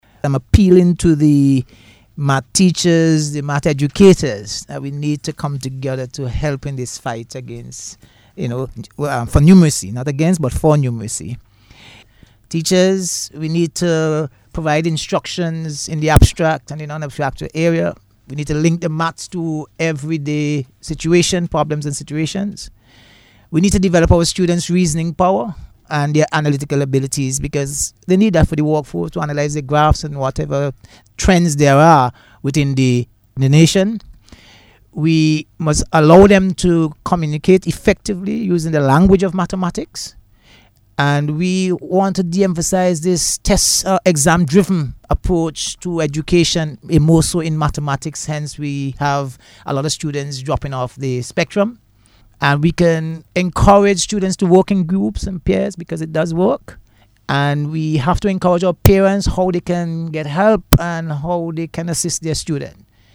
As part of the activities a Radio Discussion Program was held yesterday focusing on the State of Mathematics in St. Vincent and the Grenadians and its implications for national development.